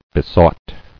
[be·sought]